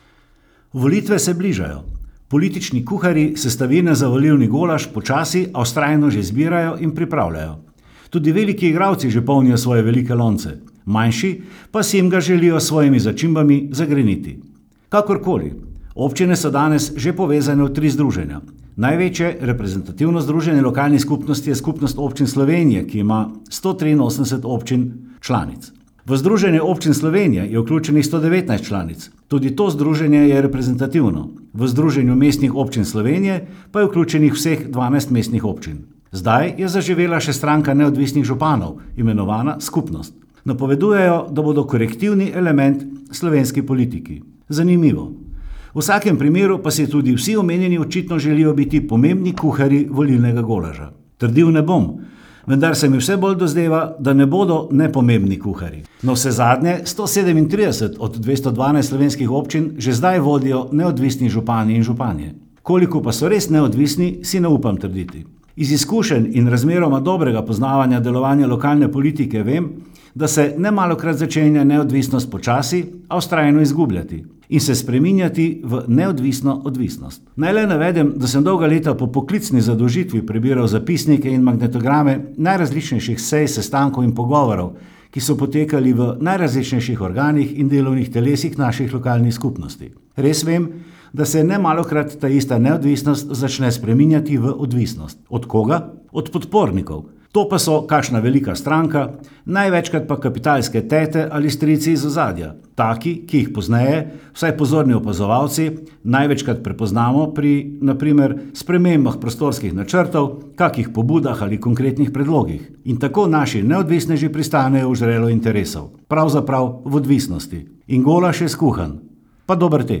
komentar .mp3